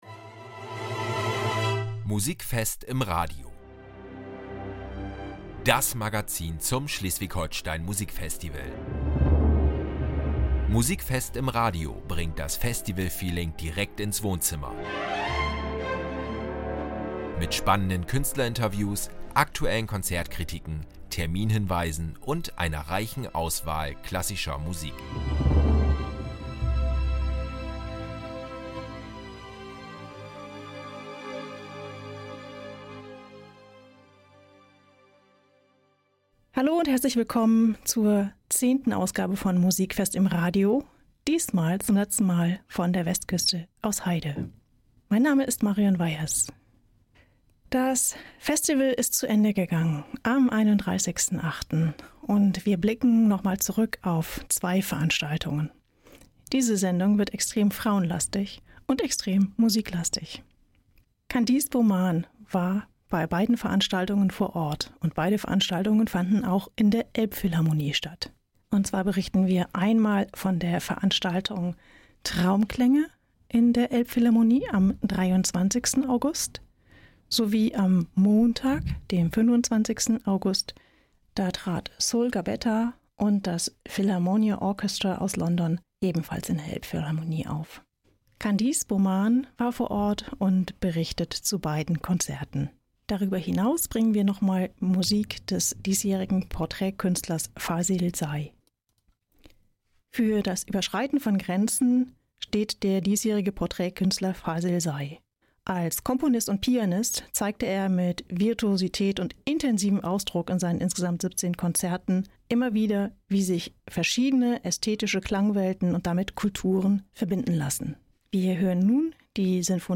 In der zehnten und gleichzeitig vorletzten Folge von “Musikfest im Radio” entführt das Konzert “Traumklänge” in sphärische Musikwelten und Cellistin Sol Gabetta glänzt mit dem Philharmonia Orchestra unter Santtu-Matias Rouvali in der Hamburger Elbphilharmonie.